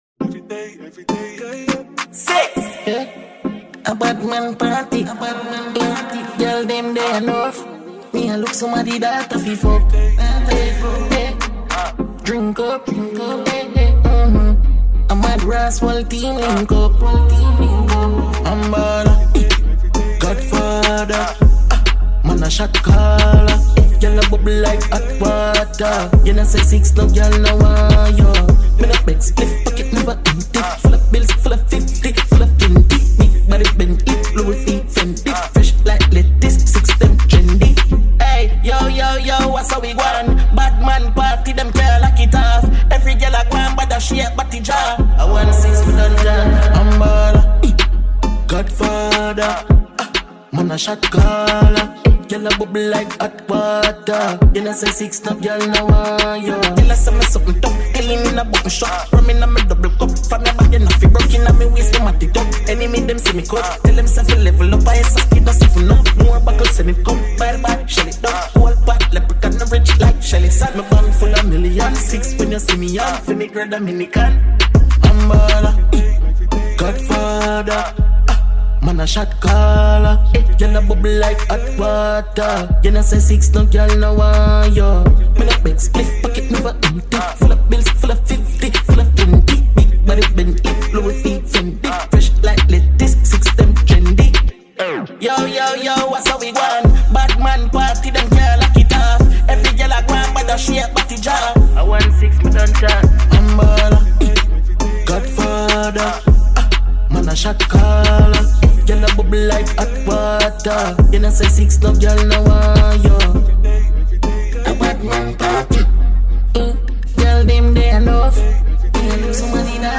Dancehall musician